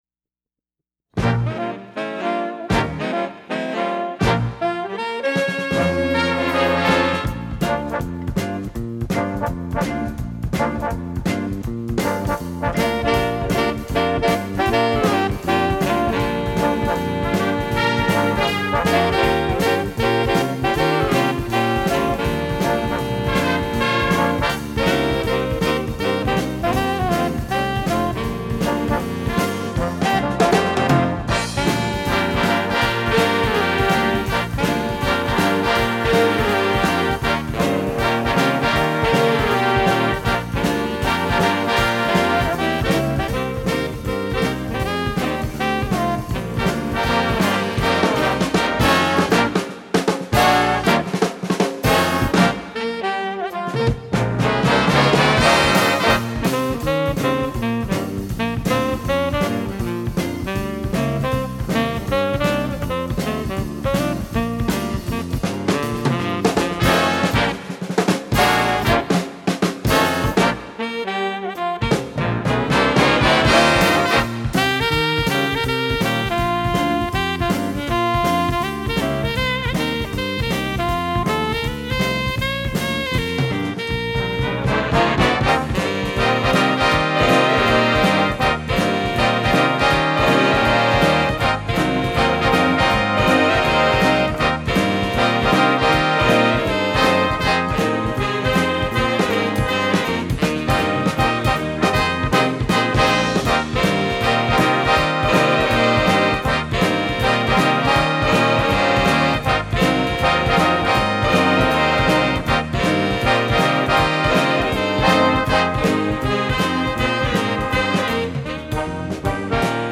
Répertoire pour Jazz band